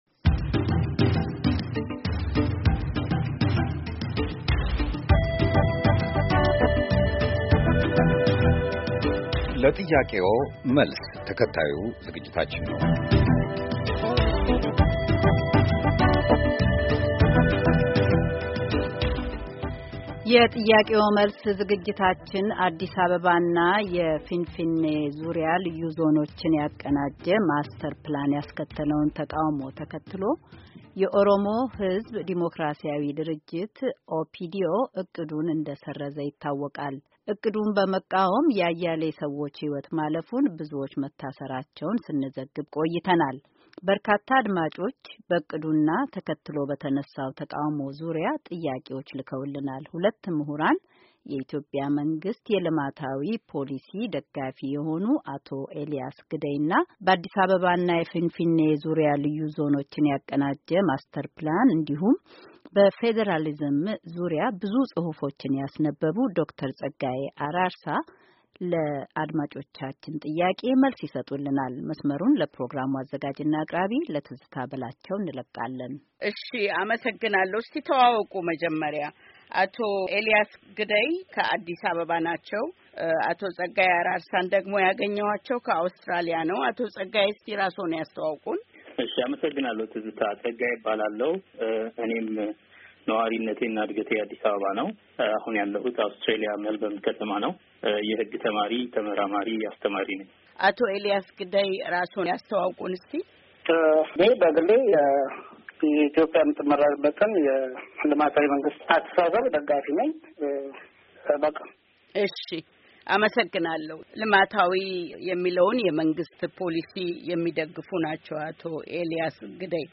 የአዲስ አበባ እና የፊንፊኔ ልዩ ዞን የተቀናጀ የጋራ ማስተር ፕላን ተግባራዊ ይደረጋል መባሉ ያስከተለው ተቃውሞን ተከትሎ የኦሮሞ ሕዝብ ዴሞክራሲያዊ ድርጅት - ኦሕዴድ ዕቅዱን መሠረዙ ይታወቃል፡፡በርካታ አድማጮች በዕቅዱና ዕቅዱን ተከትሎ በተነሣው ተቃውሞ ዙሪያ ጥያቄዎች ልከውልናል፡፡የጥያቄዎ መልስ ዝግጅታችን ሁለት ምሑራን ጋብዟል፡፡